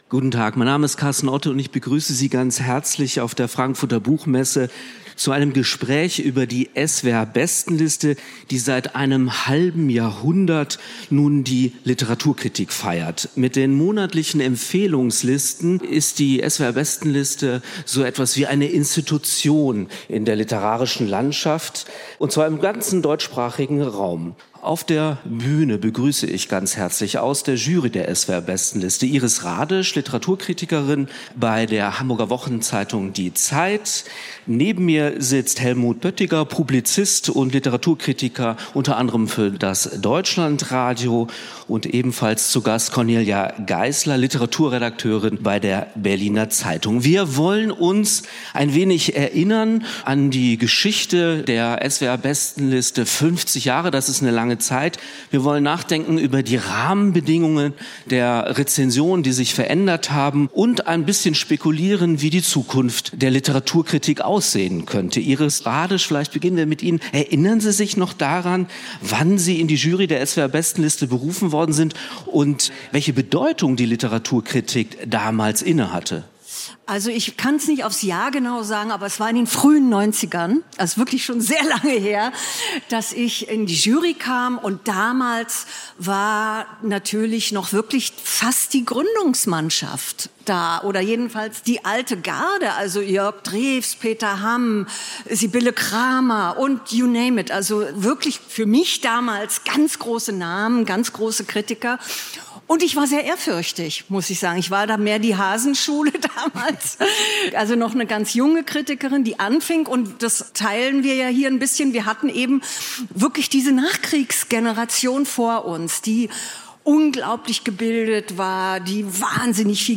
Seit 50 Jahren prägt die SWR Bestenliste die Literaturkritik. Auf der Buchmesse diskutiert die Jury über Zukunft, Relevanz und Mut zur Kontroverse in der Kritik.